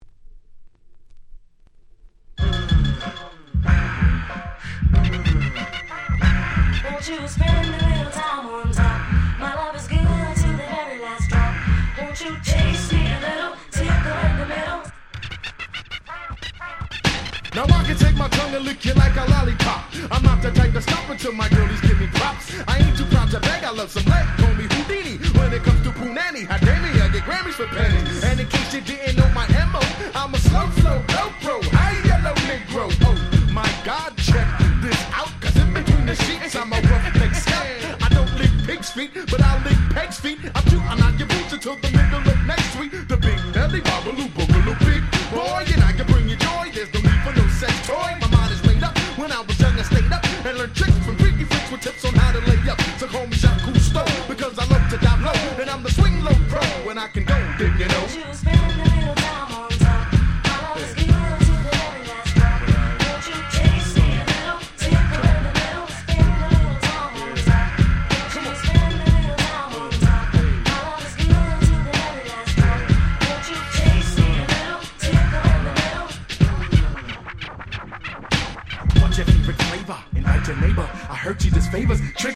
90's ブーンバップ